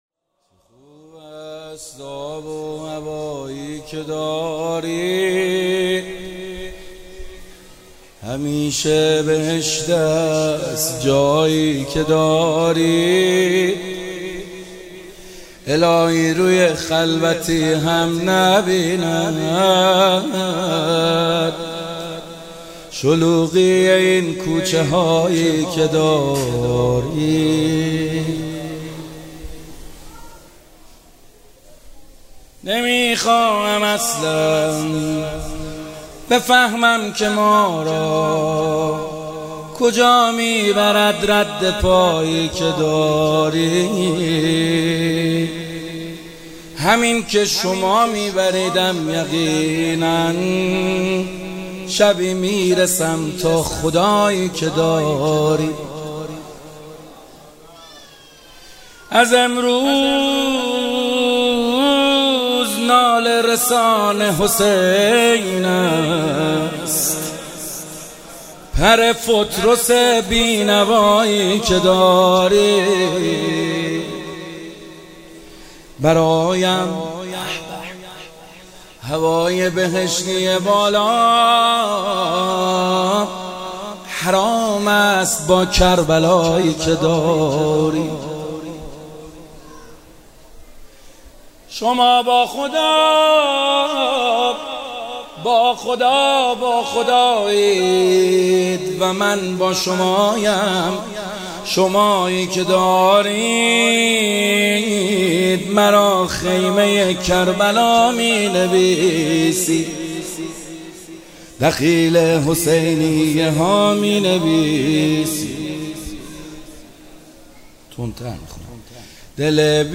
ولادت امام حسین - مدح خوانی - محمد حسین حدادیان